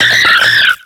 Cri de Négapi dans Pokémon X et Y.